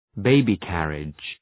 Προφορά
{‘beıbı,kærıdʒ} (Ουσιαστικό) ● αμαξάκι μωρού